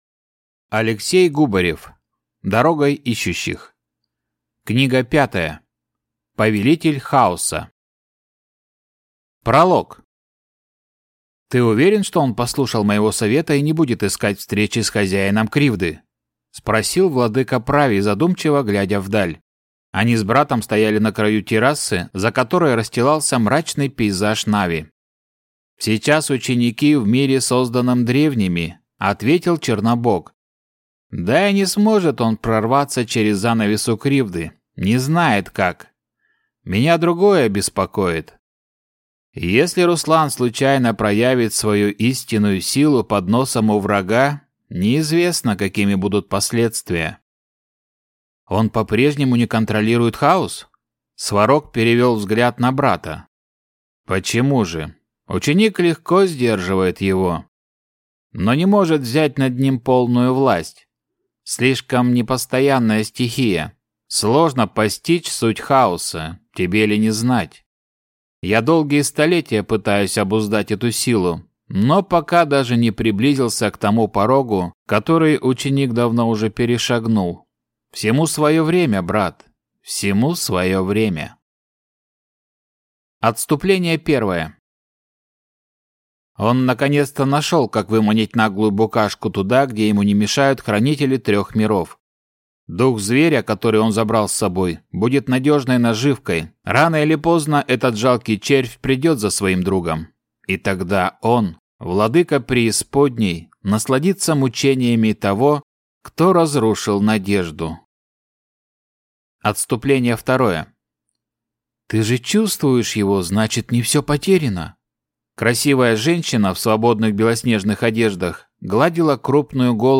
Аудиокнига Повелитель Хаоса. Книга 5 | Библиотека аудиокниг